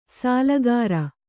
♪ sālagāra